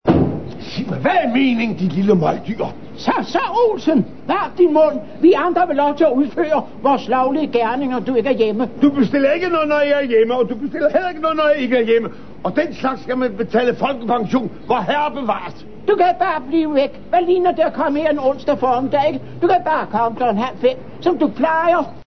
Mandig, brovtende og teatralsk.